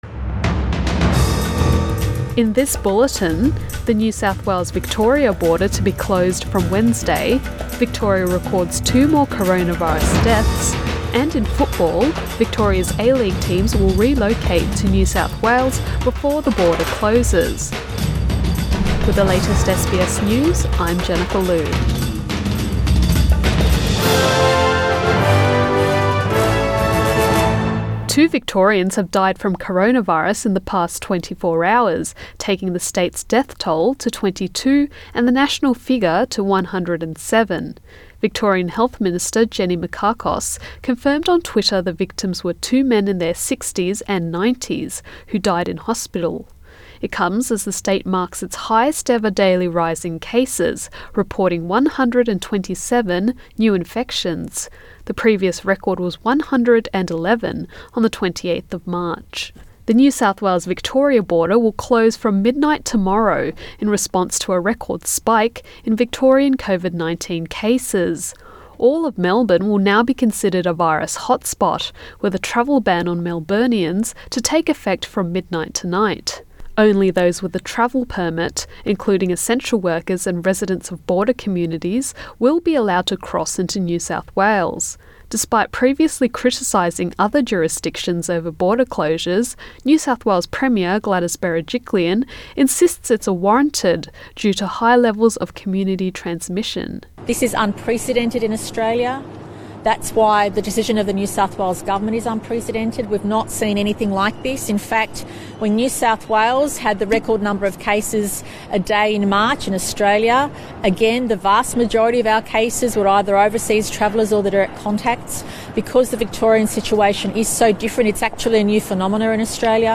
PM bulletin 7 July 2020